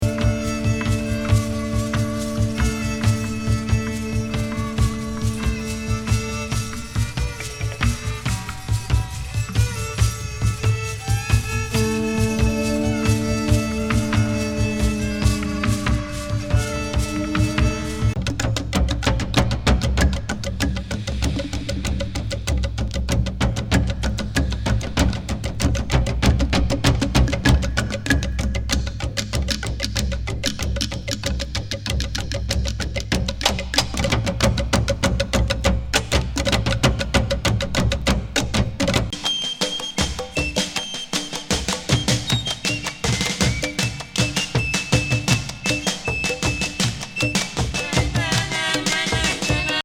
メキシカン・エクスペリメンタル・サイケ・グループ87年作。
儀式パーカッション